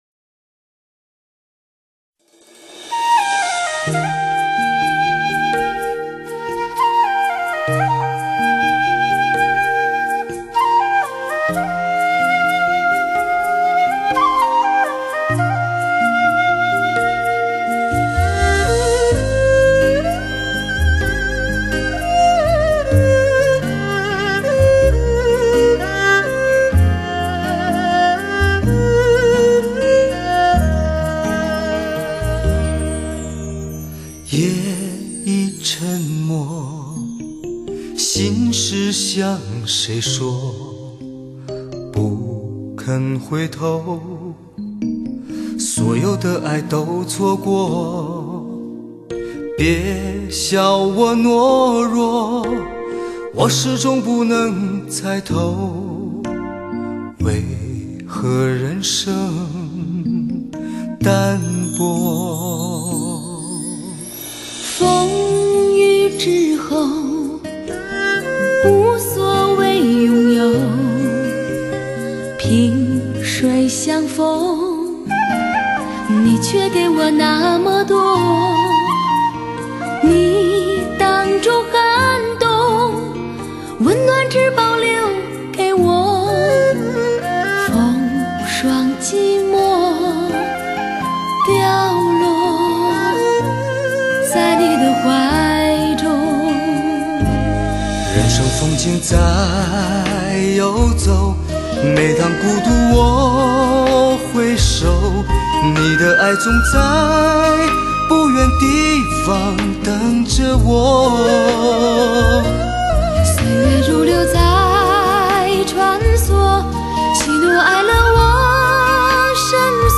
浓浓的黑胶味，暖暖的胆机声。
真正实现高清，全方位360环绕3D立体音效。